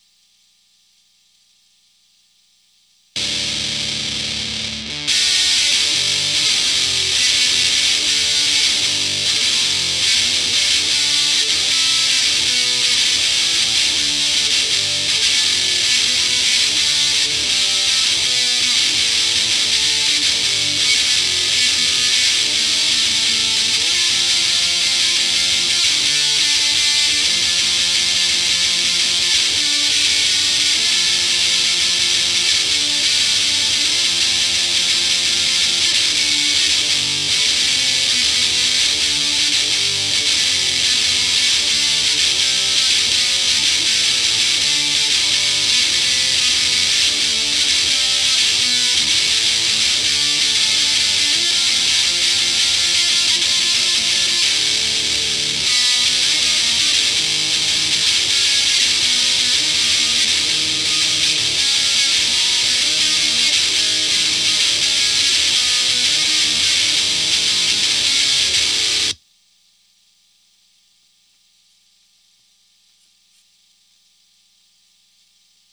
Tried with amp grill off and grill on.